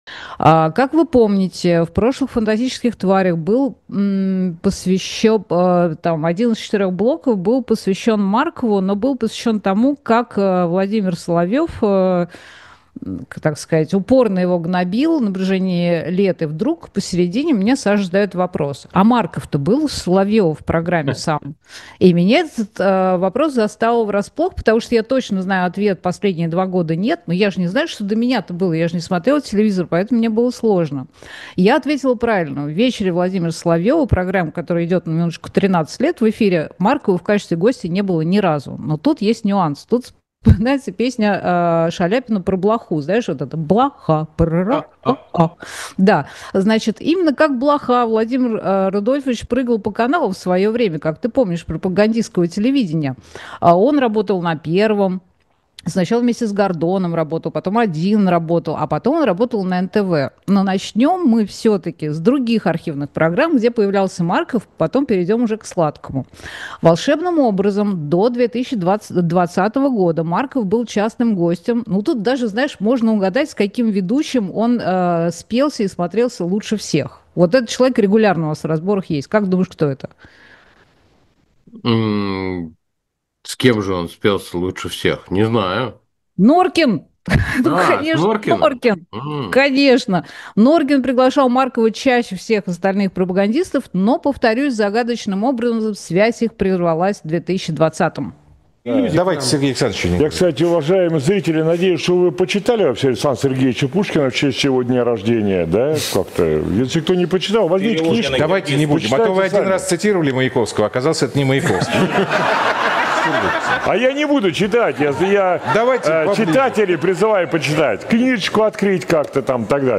Фрагмент ночного эфира Александра Плющева